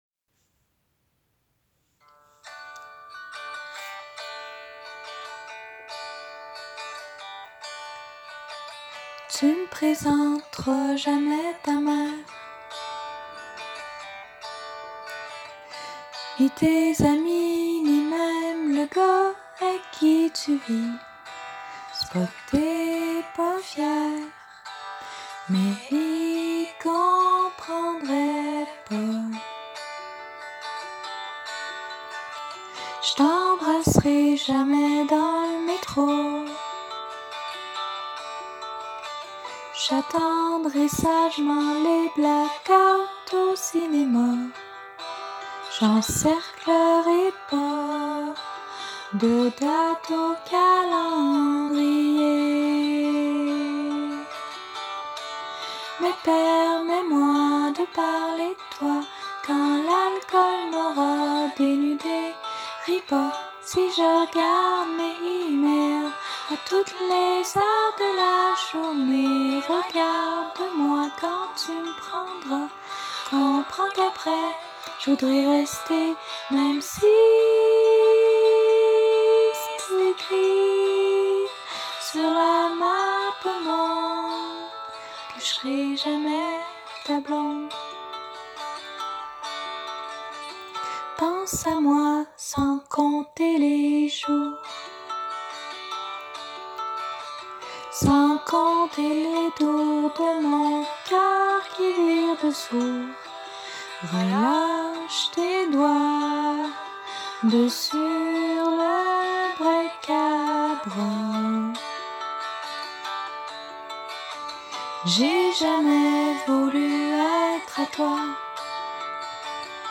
Soothing, cruisey and easy to listen to.